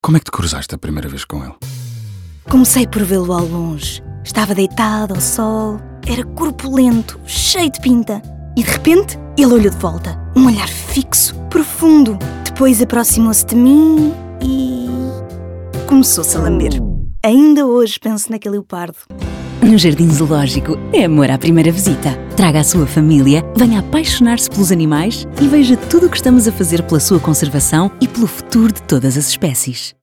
Spot de rádio 1